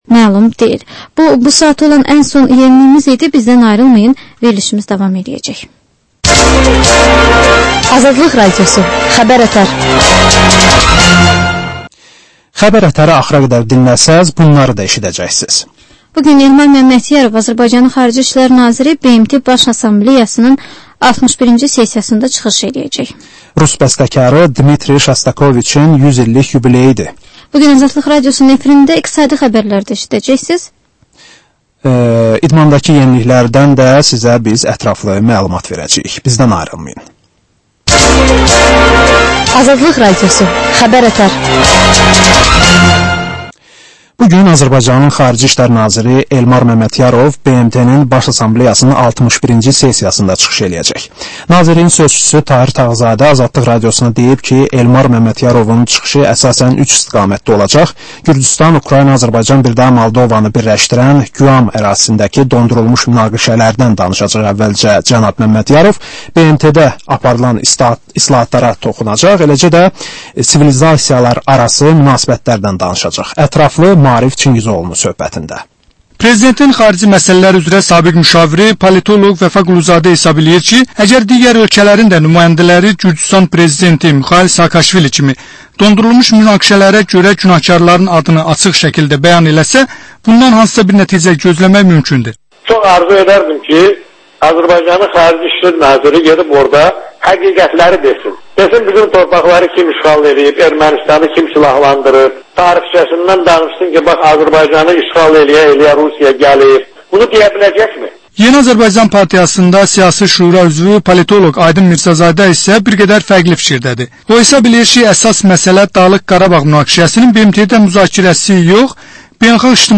Həftənin mədəniyyər xəbərləri, reportajlar, müsahibələr